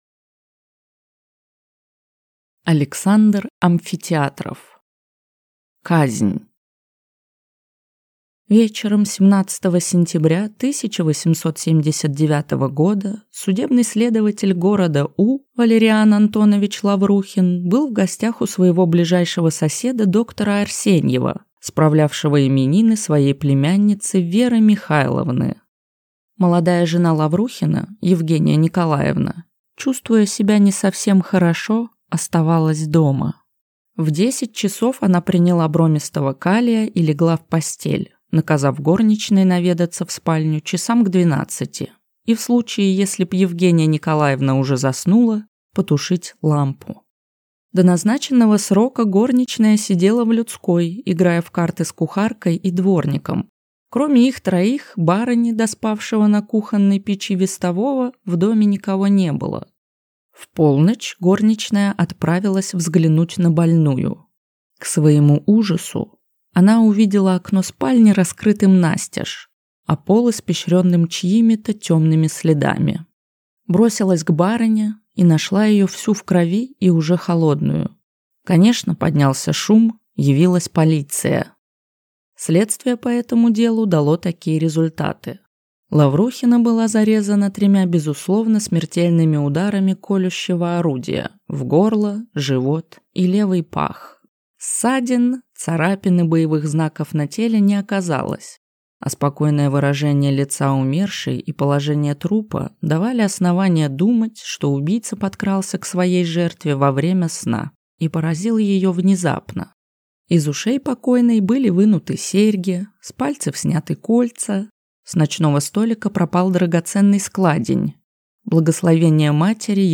Аудиокнига Казнь | Библиотека аудиокниг